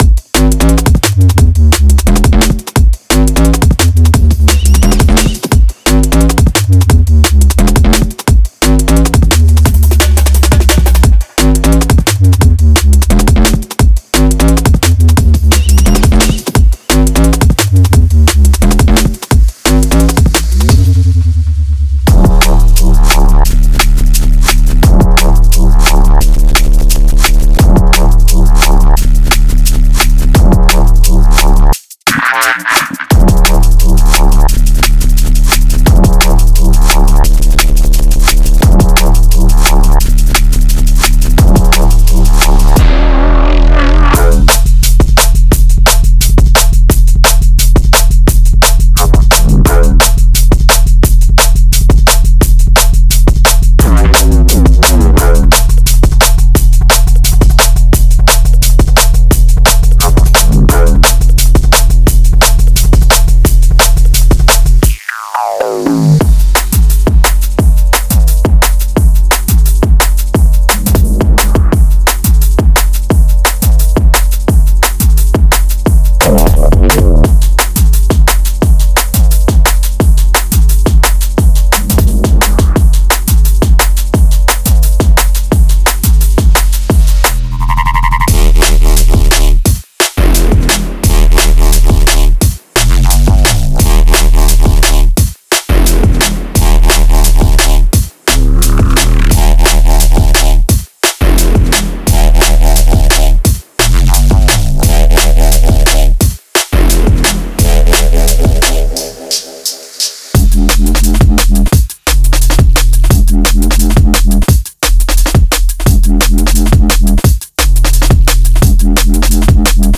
Genre:Drum and Bass
全体を通して、動き、キャラクター、そして圧倒的な重量感を感じられます。
グルーヴのポケットにしっかりと収まり、リズムを前へと押し進めるタイプのベースです。
💥 ディープなサブ、ローリングするミッド、ウォブルベースライン
注記：デモで使用されているドラムは本パックには含まれていません。
デモサウンドはコチラ↓